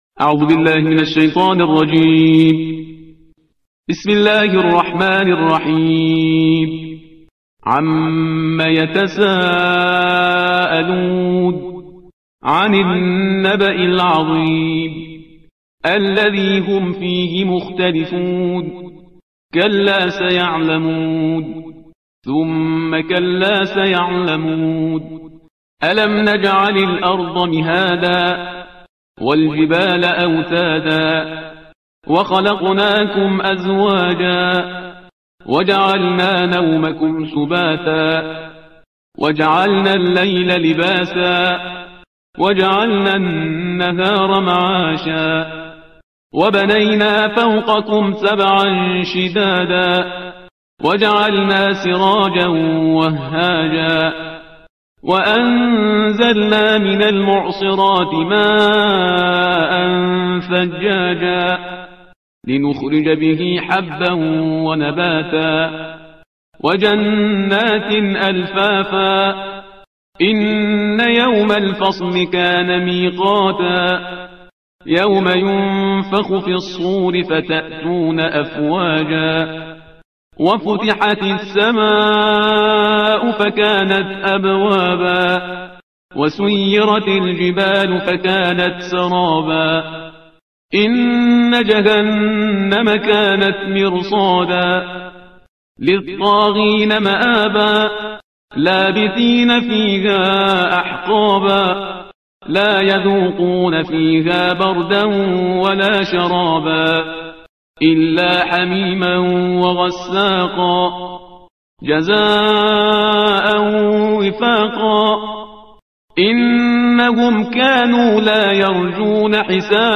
ترتیل صفحه 582 قرآن – جزء سی ام